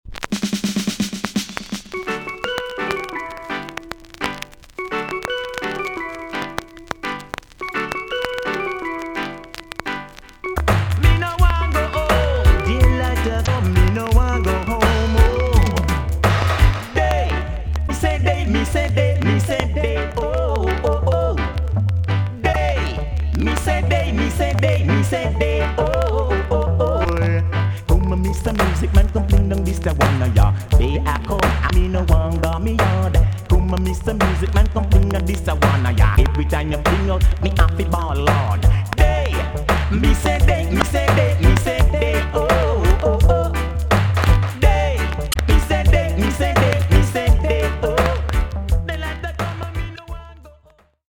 TOP >80'S 90'S DANCEHALL
B.SIDE Version
VG+~VG ok 少し軽いチリノイズが入ります。